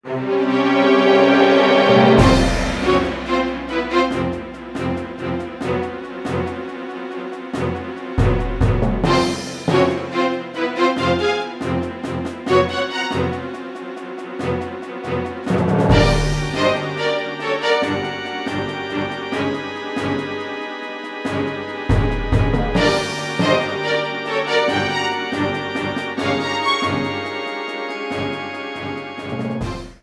bowed string arrangement
pipe organ accompaniment